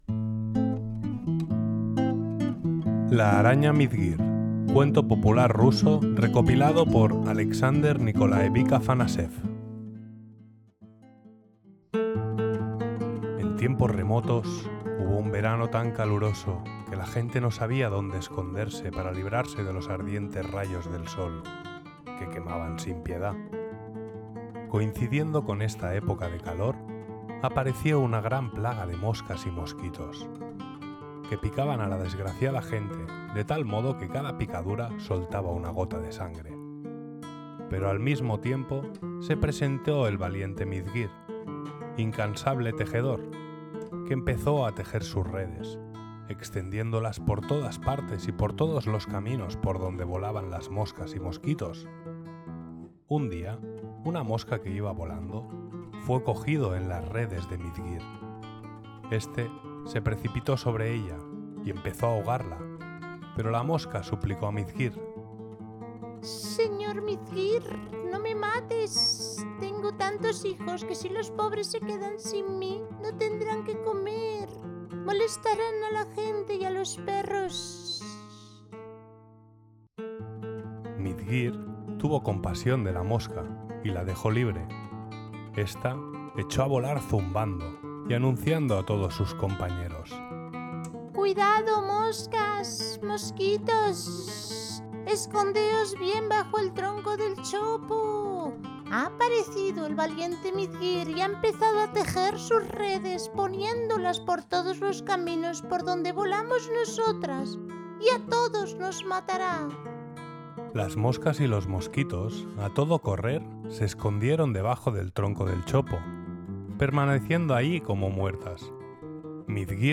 “La araña Mizguir” es un cuento del folclore ruso, recopilado por Aleksandr Nikolaevich Afanasev en sus colecciones de cuentos populares rusos. Narrado con la música “Spider Dance” de Andrew York, acompañado de ilustraciones de Arthur Rackham, este relato forma parte del canal ConMoraleja, donde convergen cuentos, música e ilustración para…